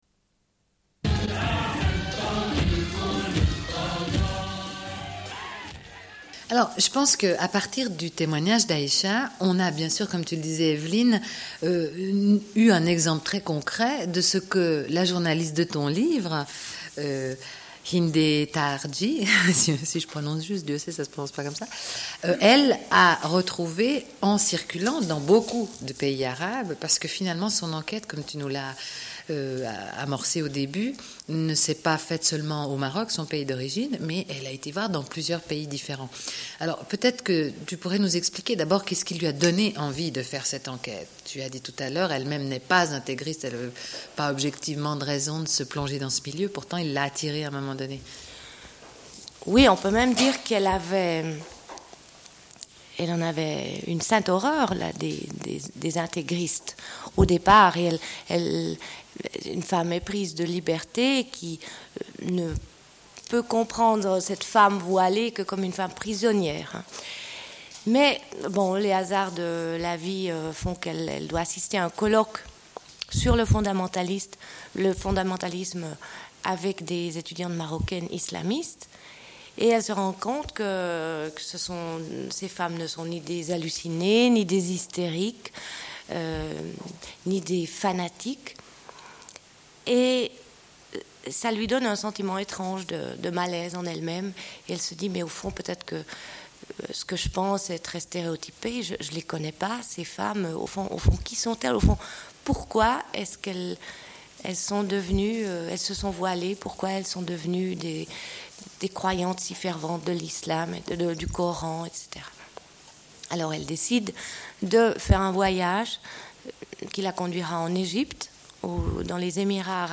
Une cassette audio, face B00:29:20